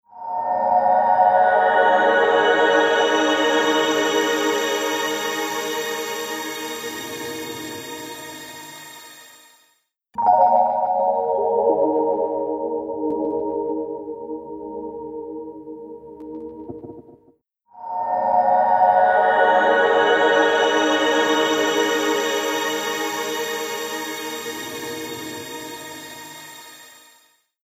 ピッチシフティングを融合した、幻想的なリバーブ
ShimmerVerb | Synth | Preset: Mallet Angels
ShimmerVerb-Eventide-Ambient-Synth-Mallet-Angels.mp3